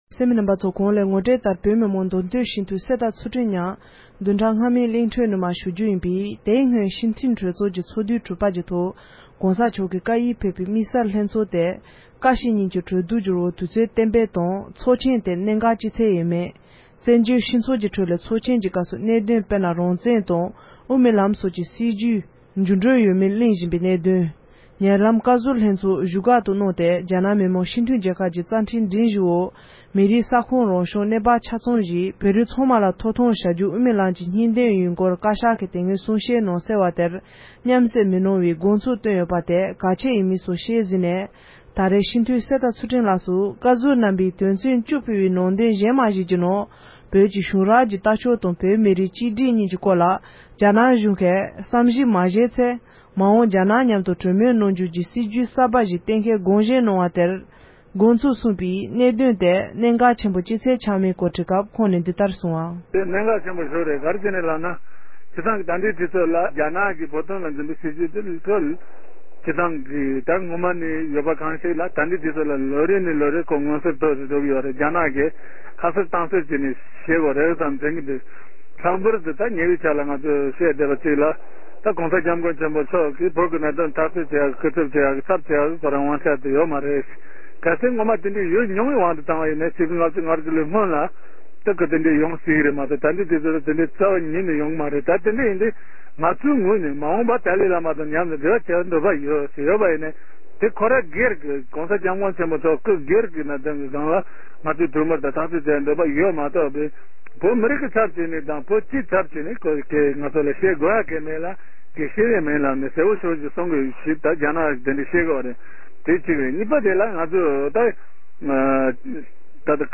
མདོ་སྟོད་སྤྱི་འཐུས་གསེར་རྟ་ཚུལ་ཁྲིམས་ལགས་ཀྱིས་བཀའ་ཟུར་ལྷན་ཚོགས་དང་འབྲེལ་བའི་རྒྱ་བོད་གཉིས་ཀྱི་གནད་དོན་སྐོར་གསུངས་བ།